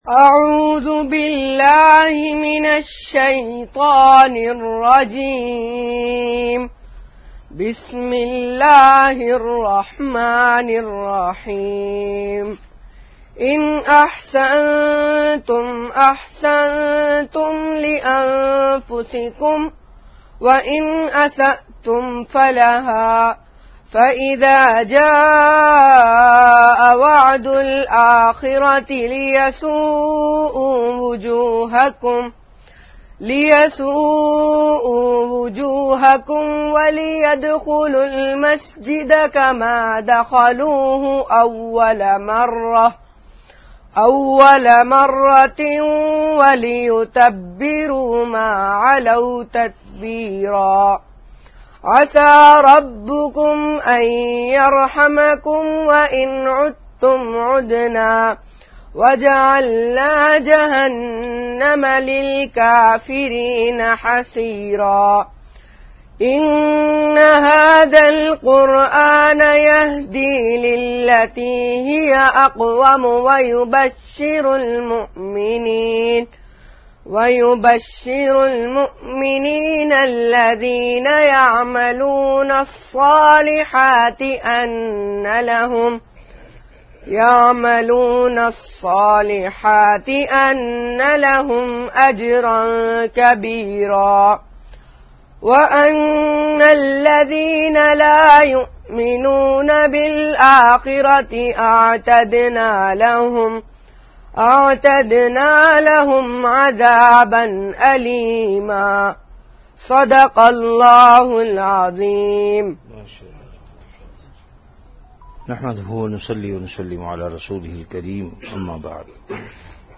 Delivered at Madinah Munawwarah.